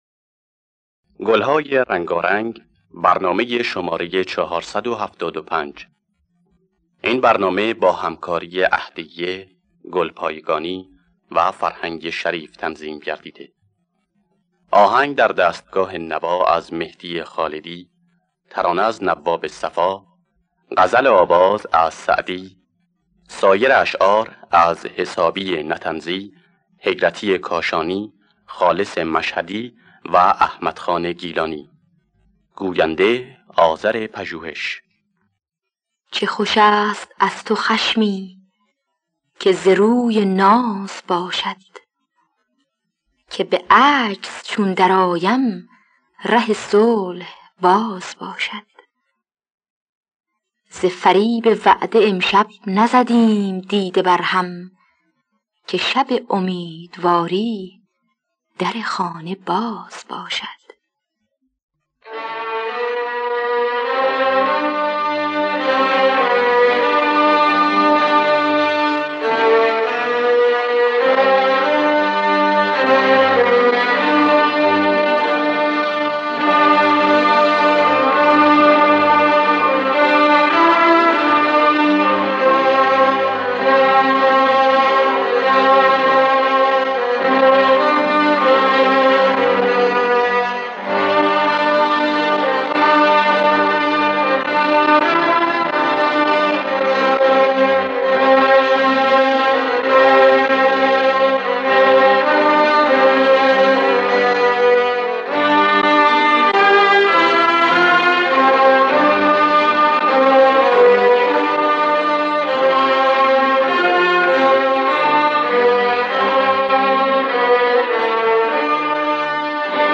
در دستگاه نوا